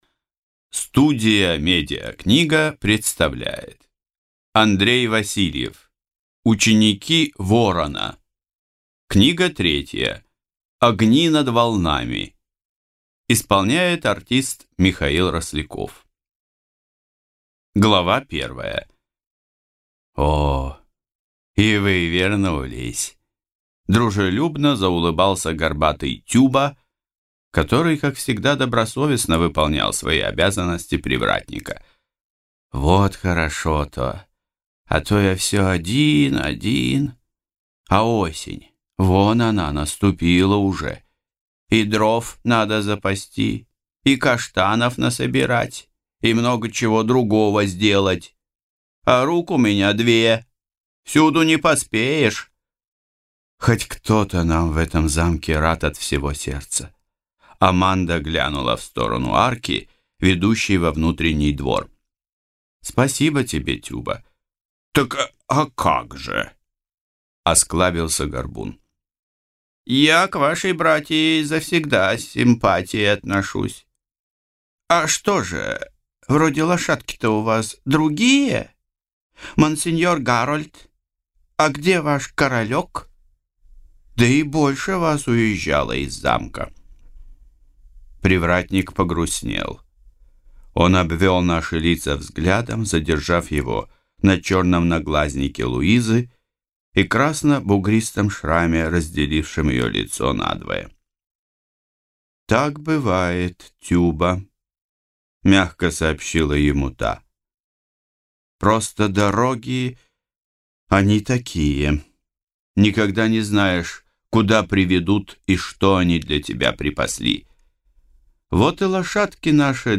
Аудиокнига Огни над волнами | Библиотека аудиокниг